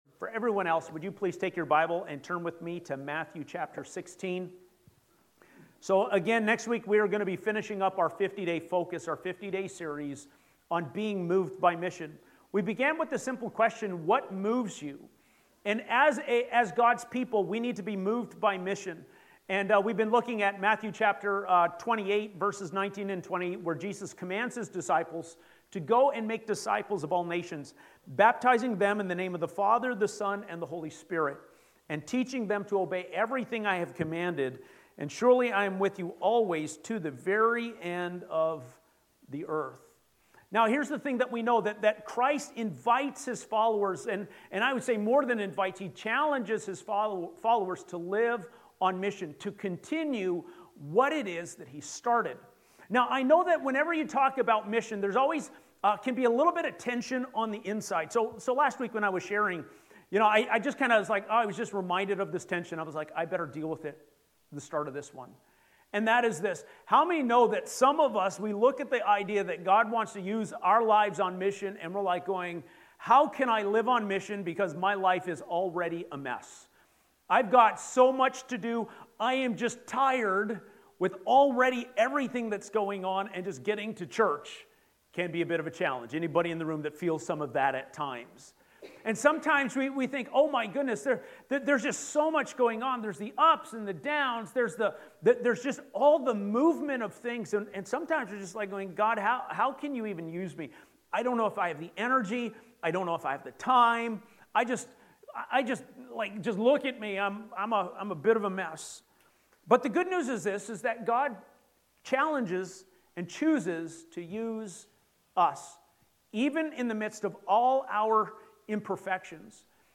Sermons | Asbury Church